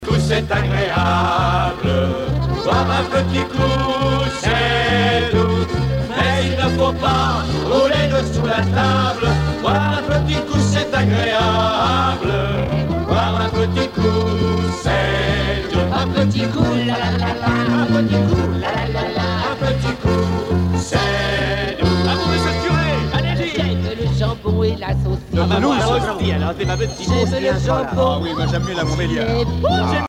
danse : marche
circonstance : bachique
Pièce musicale éditée